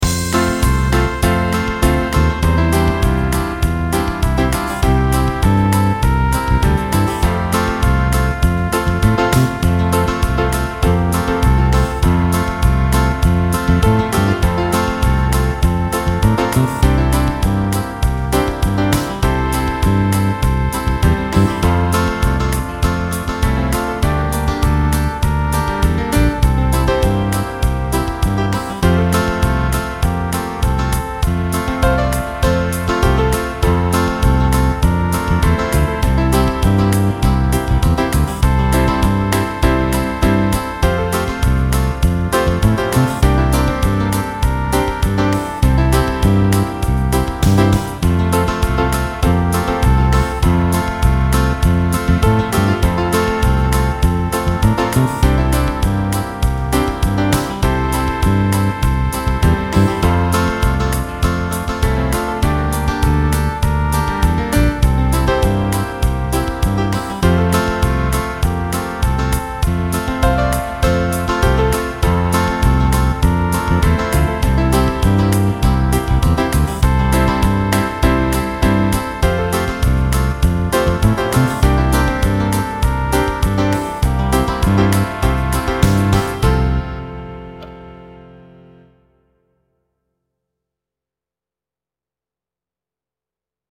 TROMBA DUETTO
TROMBA DUO • ACCOMPAGNAMENTO BASE MP3
Tromba 1
Tromba 2